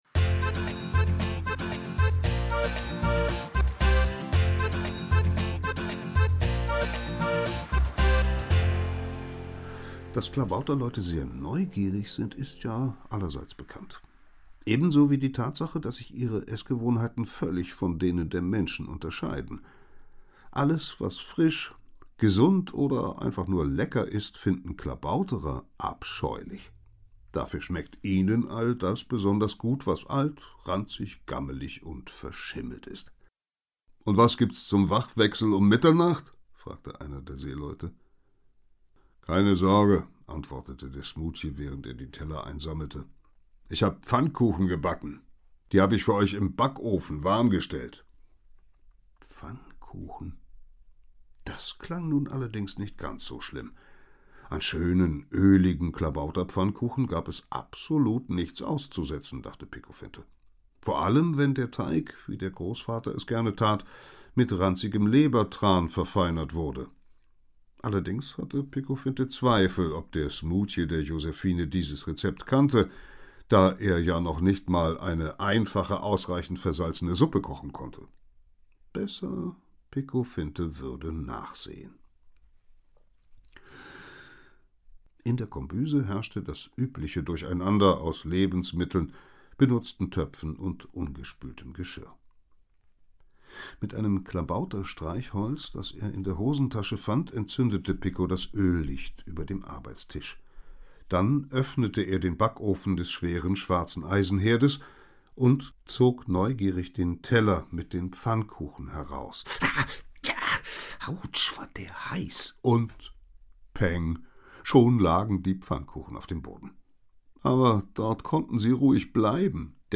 Hörbuch, 2 CDs, ca. 145 Minuten